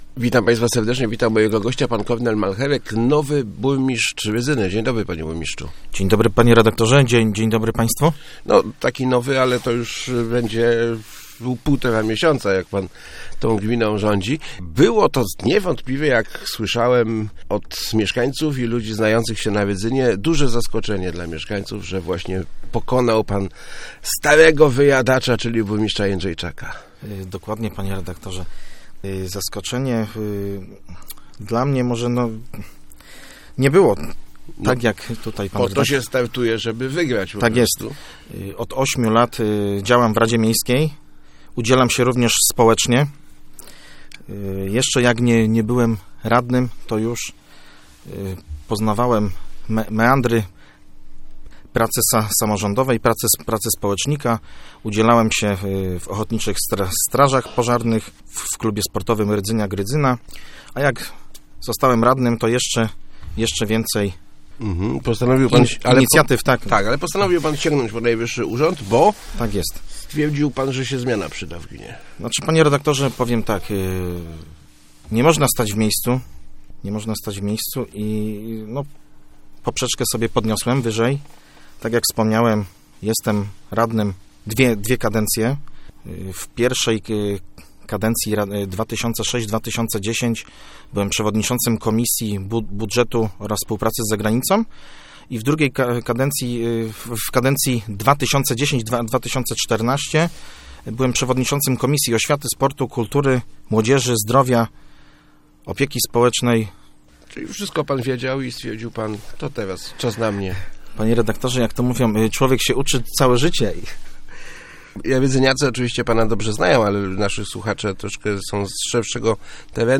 kormalch80.jpgBudowa dróg i modernizacja oczyszczalni ścieków to nasze najważniejsze inwestycje w tej kadencji - mówił w Rozmowach Elki Kornel Malcherek, burmistrz Rydzyny. Przyznał on, że gminę zastał w dobrej kondycji i nie przewiduje zmian w urzędzie.